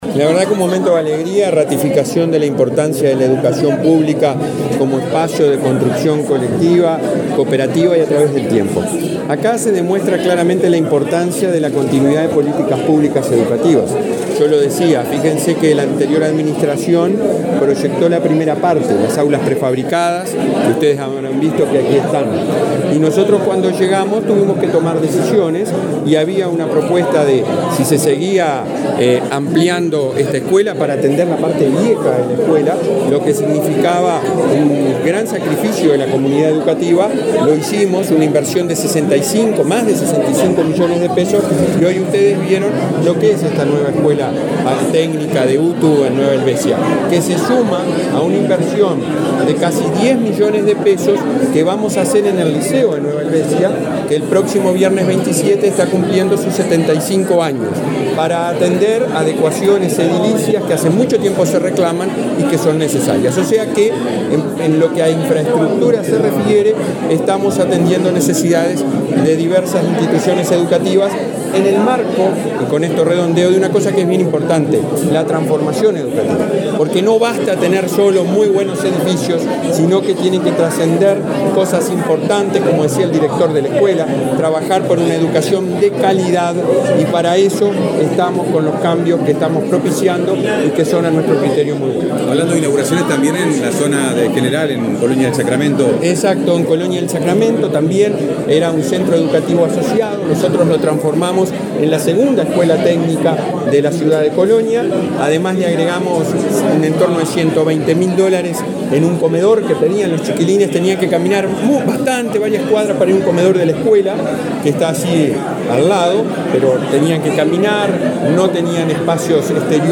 Declaraciones del presidente de la ANEP, Robert Silva
Declaraciones del presidente de la ANEP, Robert Silva 20/10/2023 Compartir Facebook X Copiar enlace WhatsApp LinkedIn Este viernes 20, el presidente de la Administración Nacional de Educación Pública (ANEP), Robert Silva, participó en la inauguración de la reforma de la escuela técnica de Nueva Helvecia, en el departamento de Colonia. Luego dialogó con la prensa.